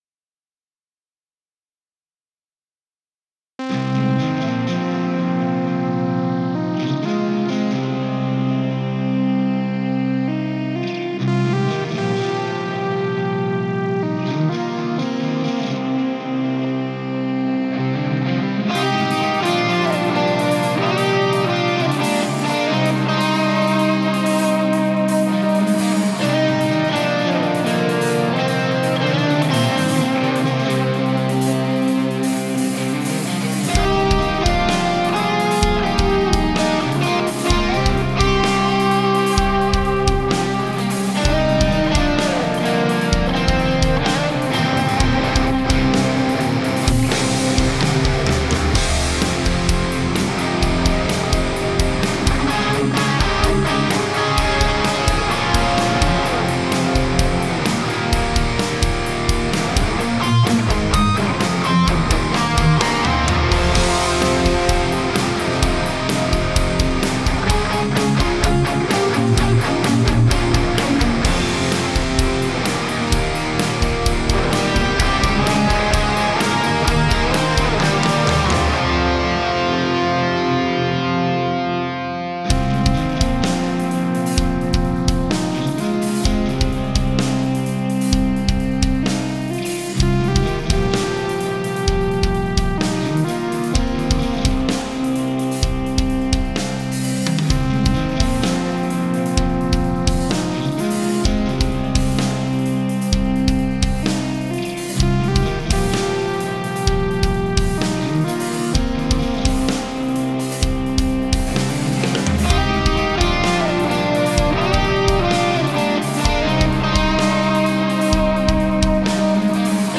Aber diesmal hab ich kein AI-Capture eines Amps benutzt. Alles kommt jetzt tatsächlich vom EnglishMan-Caline Pedal. Geboosted mit Behringer TO800 - in den THR10 von Yamaha, Clean Channel.